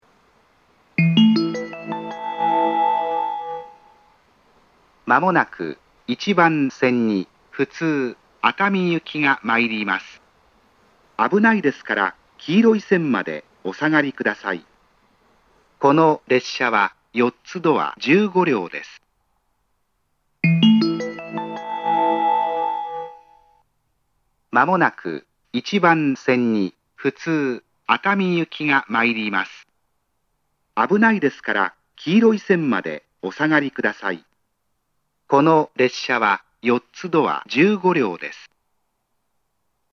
2・3番線は駅員が常駐しているため、発車時に駅員放送が被ることが多いです。
接近放送
普通　熱海行（4ドア15両）の接近放送です。